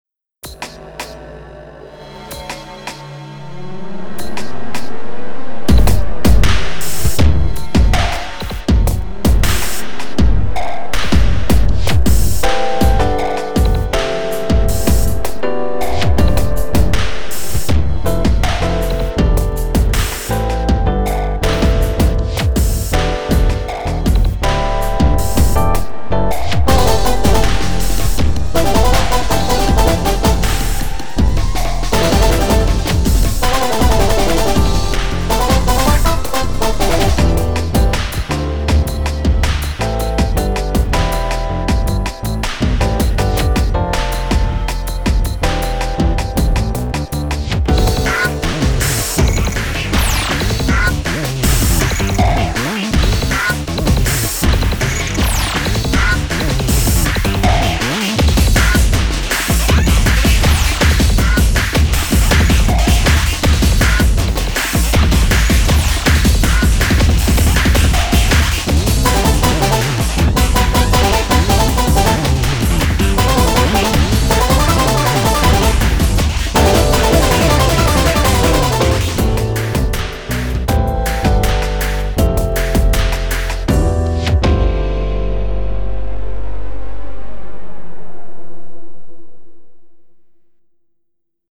BPM160
Audio QualityPerfect (High Quality)
Genre: CANTERBURY&BEAT.